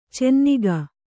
♪ cenniga